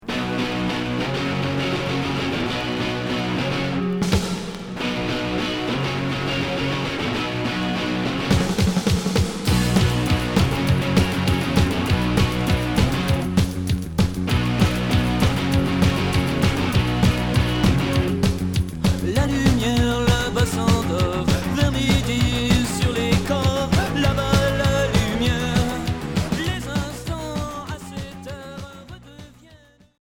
Punk rock Premier 45t retour à l'accueil